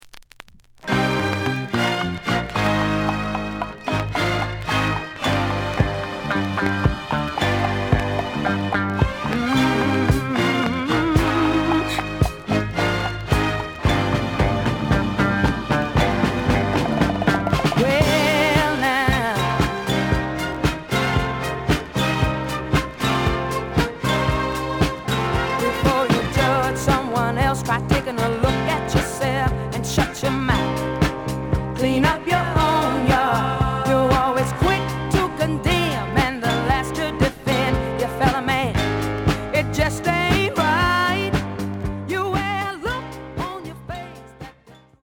The audio sample is recorded from the actual item.
●Genre: Soul, 70's Soul
Some click noise on beginning of B side due to scratches.